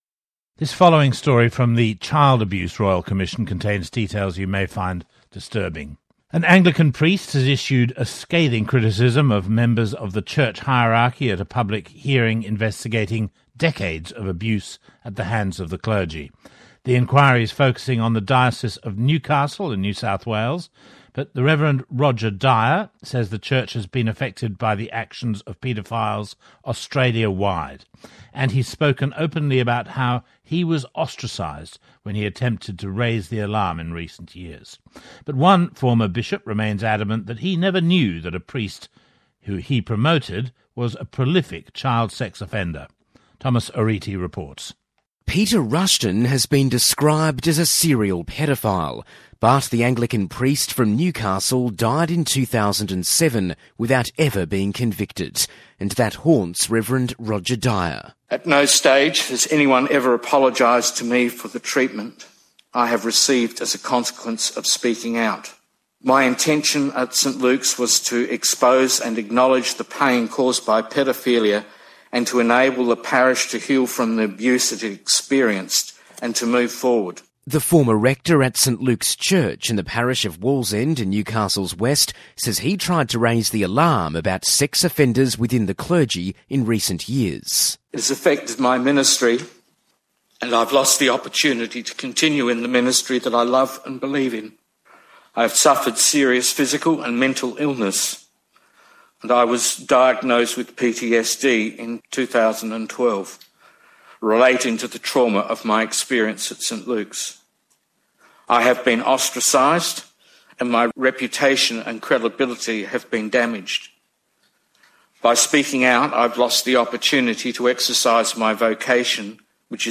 ABC Report on CARoyalComm hearing into Child Abuse within Diocese of Newcastle 04/08/16 .| MR helps stop such abuse